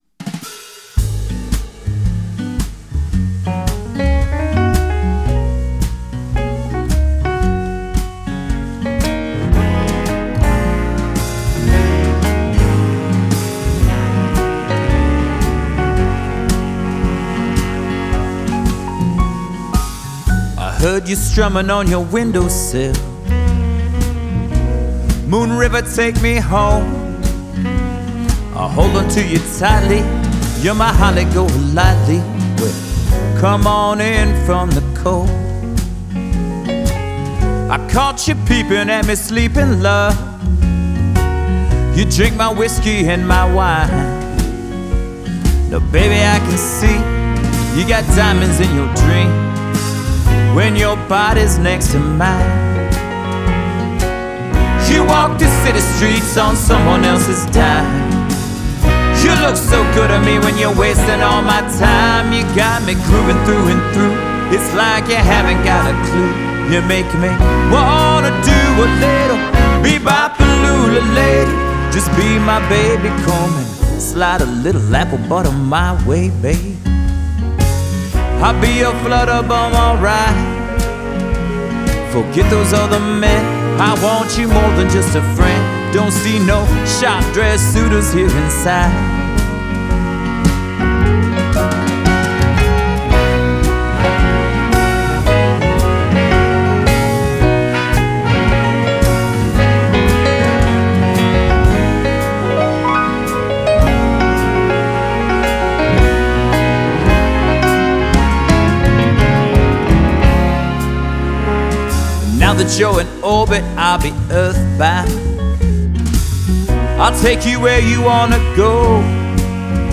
A midtempo, jazzy number
featuring a killer horn section.